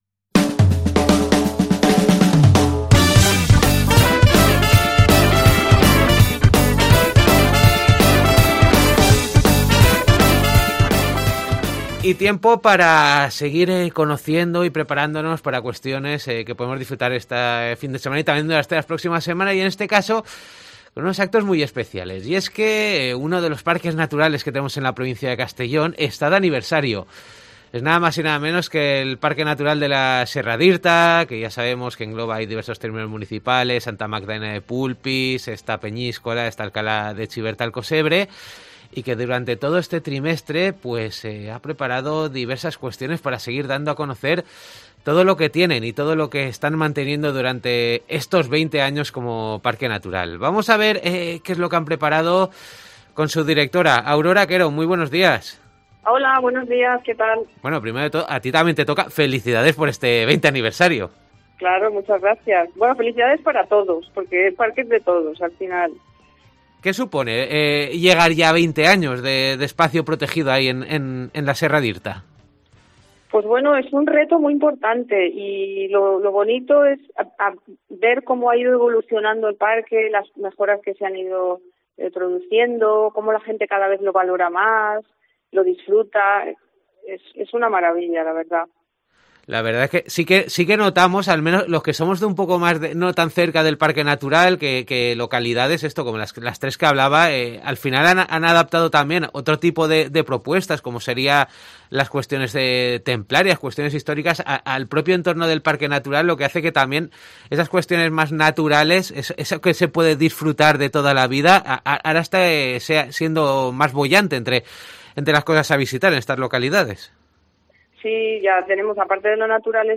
desde el parque natural Serra d'Irta